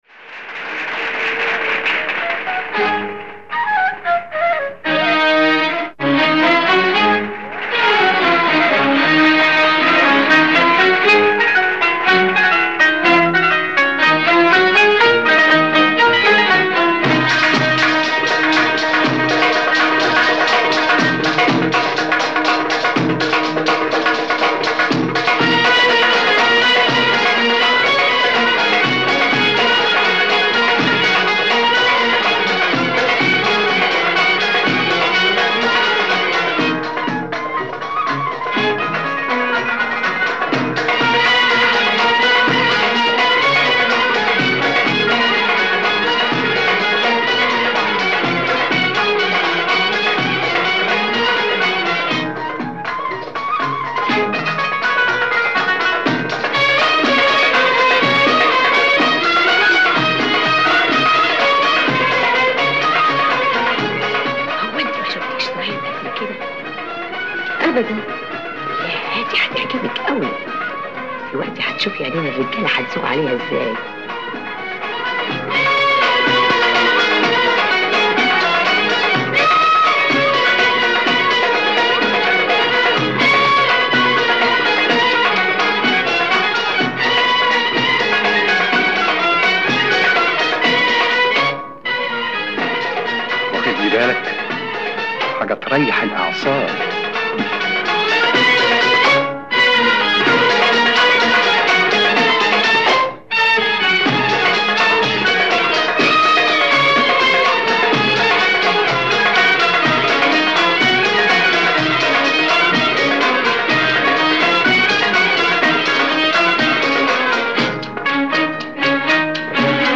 Dance Instrumental
Maqam Bayati
dance instrumental (complete recording)
full-octave descent through both ajnas
a little Sazkar here (raised 2 of Rast)